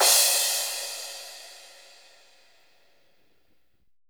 THIK CRSH.wav